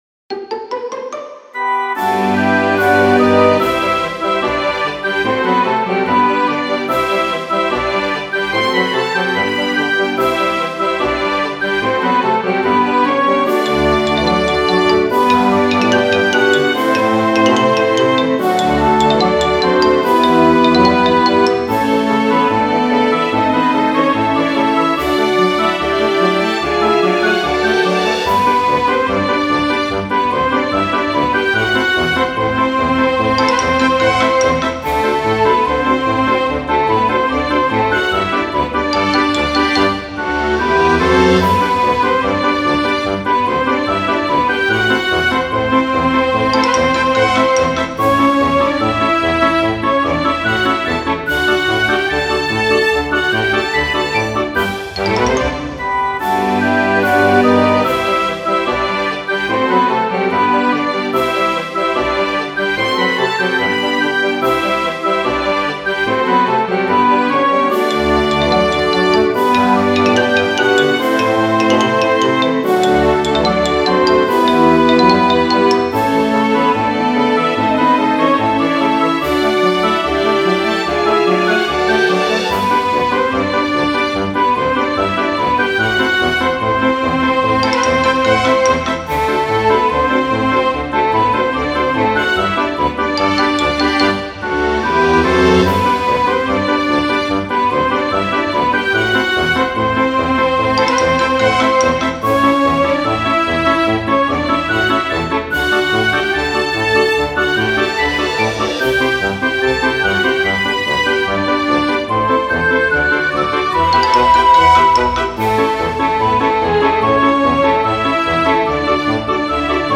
ogg(L) 楽しい オーケストラ アップテンポ
スカッと爽快なオーケストラ。